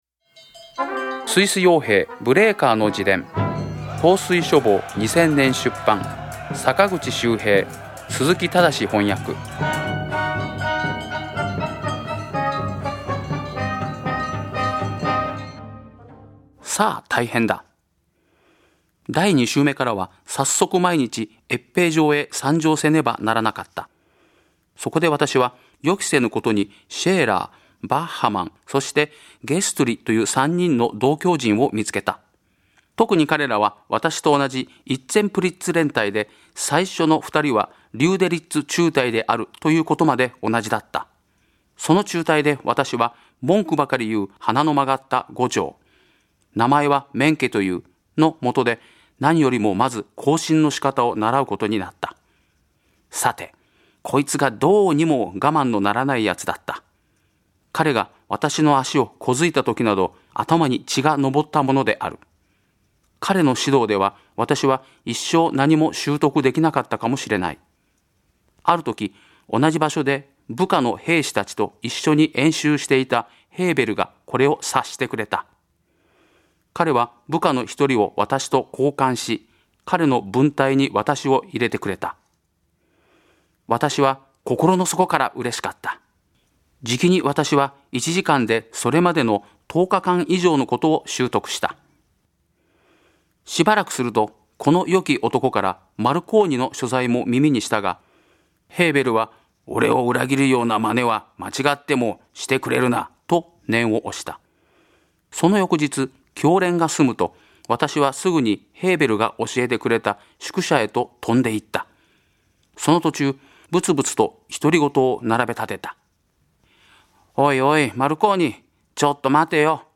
朗読『スイス傭兵ブレーカーの自伝』第50回